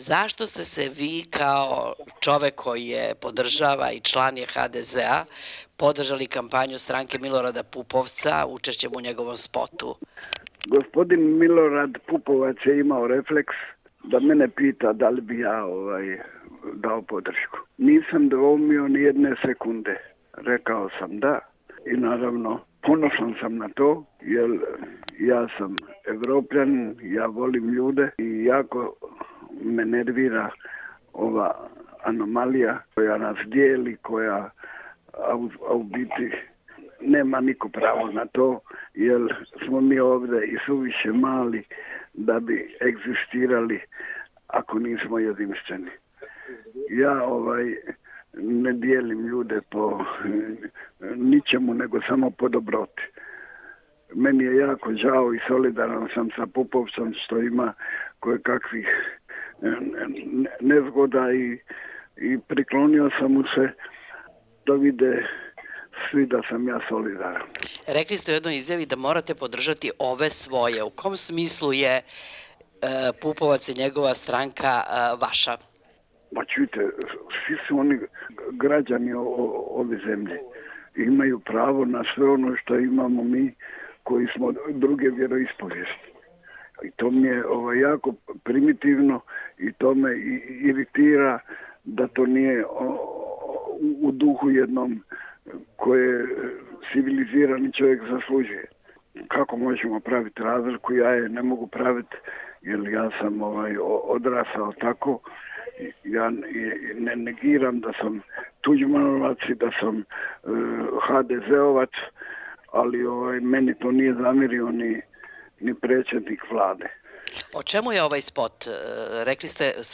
Intervju: Miroslav Ćiro Blažević